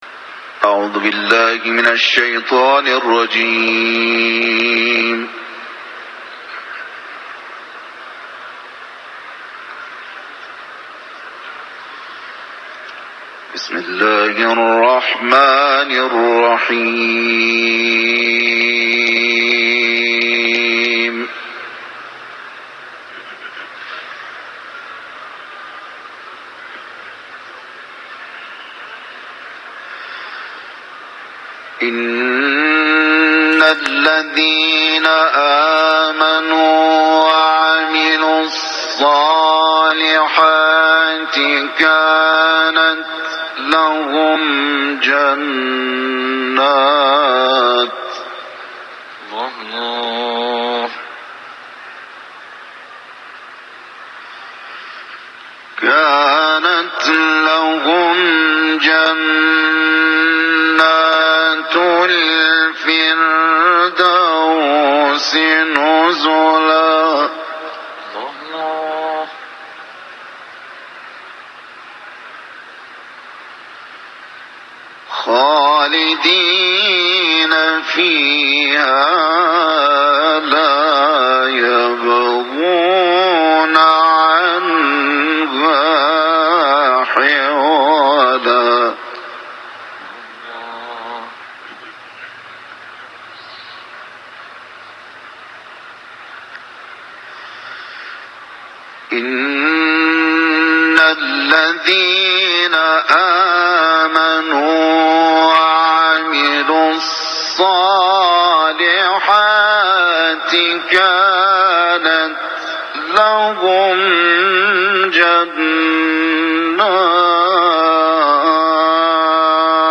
تلاوت
که در کرسی تلاوت رضوی و در جوار مرقد حضرت رضا(ع) به اجرا رسیده است